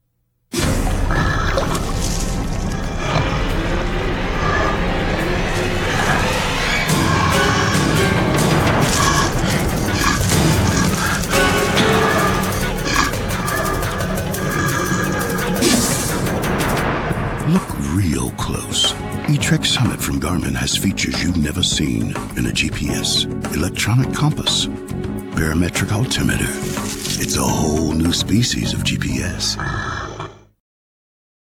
:30 TV Commercial